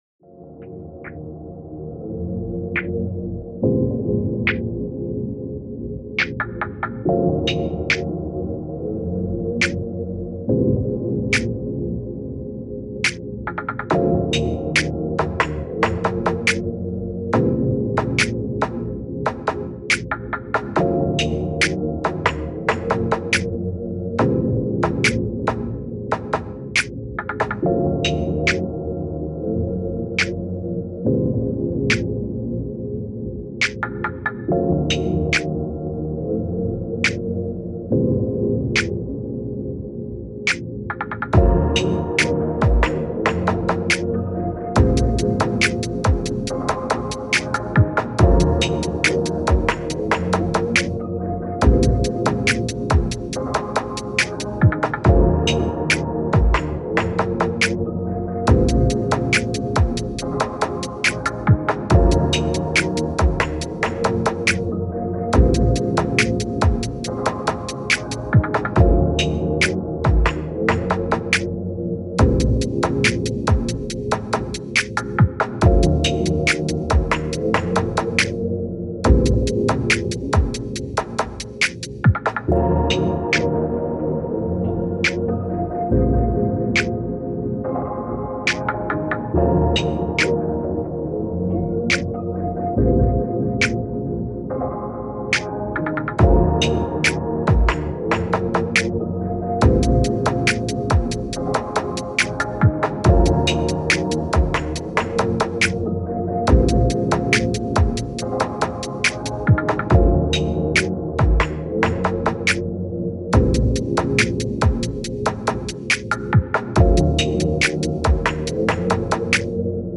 Musique chill libre de droit pour vos projets.